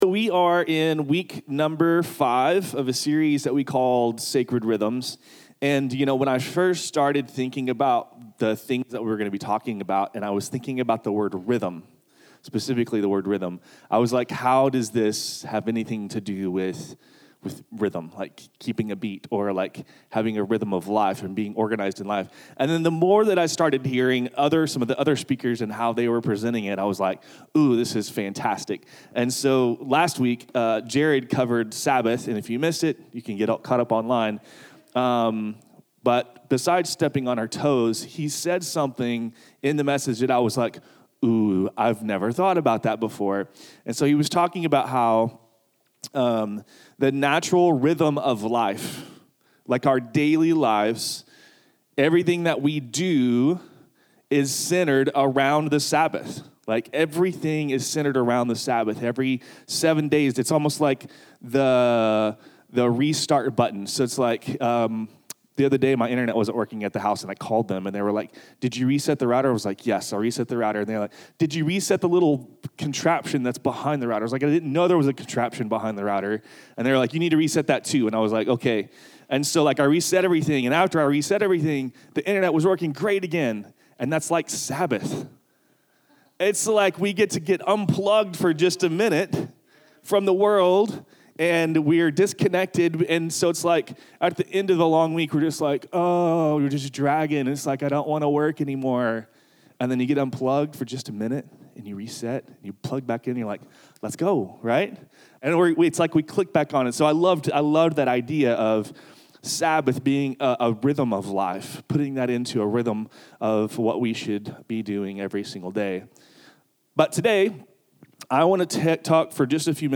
Sermons | Project Community